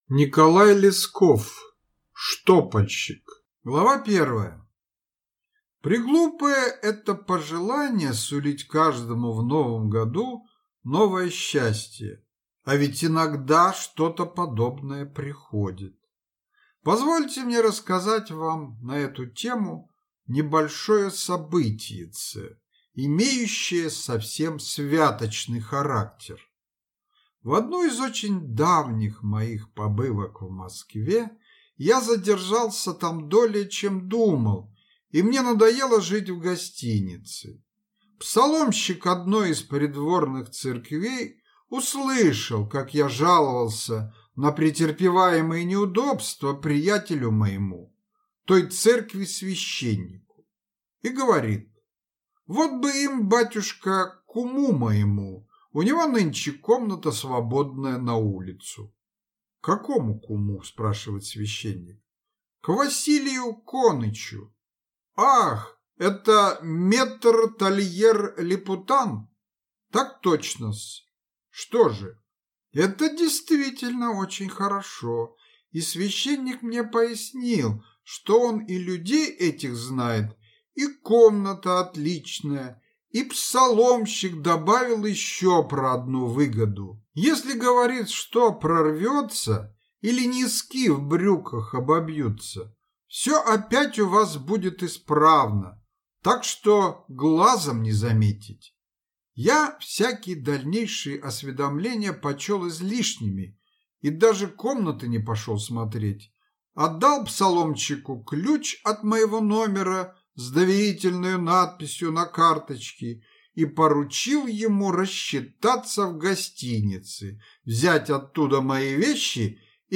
Аудиокнига Штопальщик | Библиотека аудиокниг